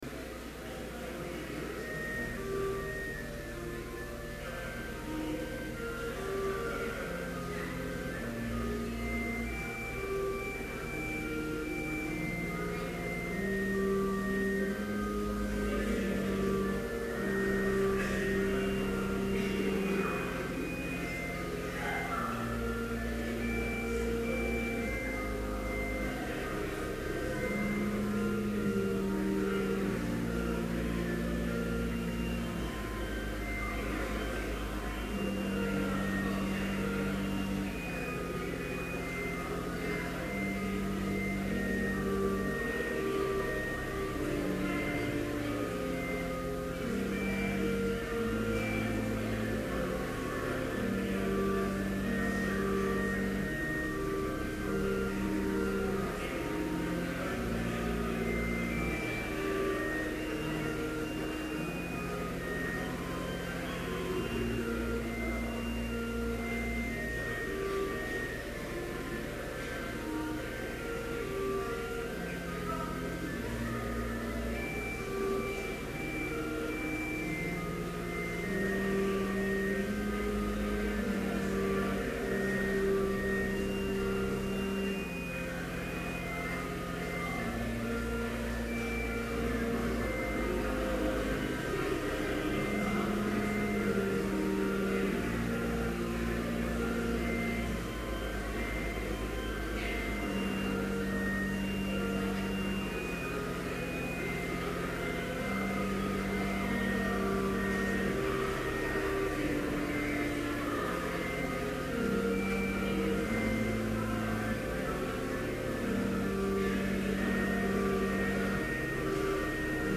Chapel worship service held on October 19, 2011, BLC Trinity Chapel, Mankato, Minnesota,
Complete service audio for Chapel - October 19, 2011